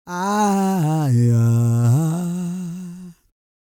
E-CROON 213.wav